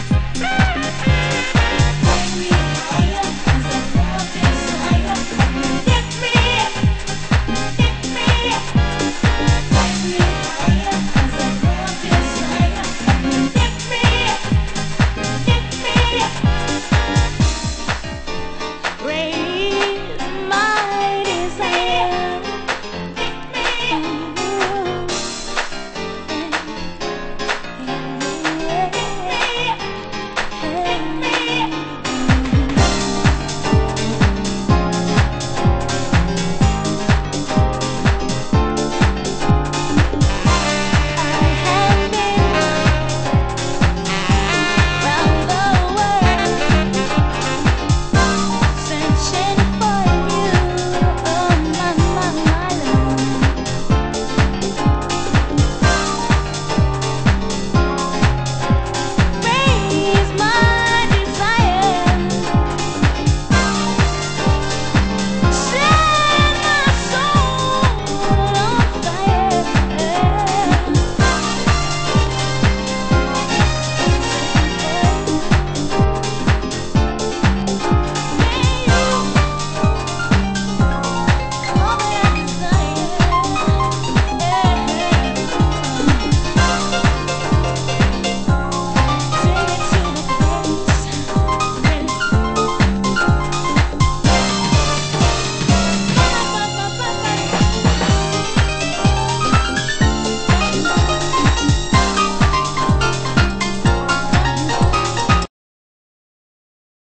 盤質：小傷による少しチリパチノイズ有